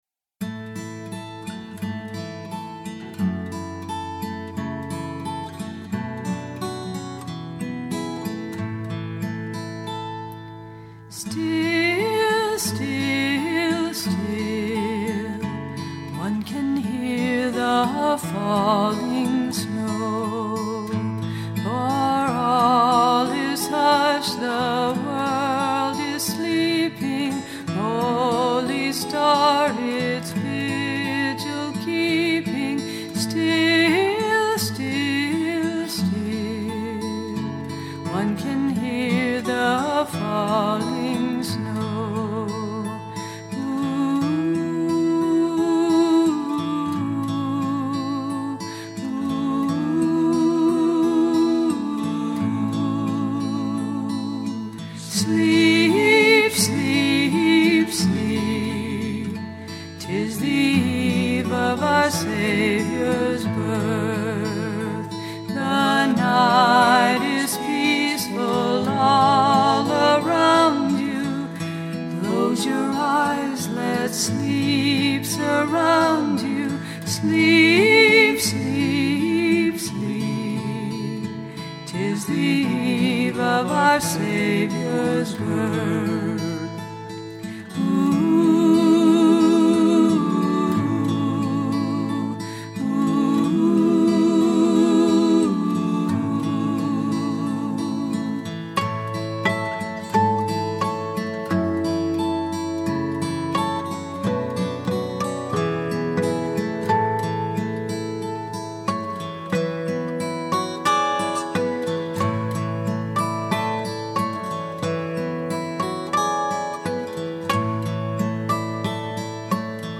Midtempo- old folk feel Fm vx, gtrs